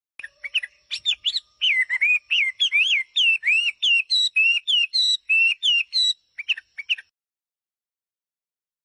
好听的野外画眉鸟叫声
画眉，也叫画眉鸟、中国画眉，属于噪鹛科，体重54-54克，体长21-21厘米。中型鸣禽。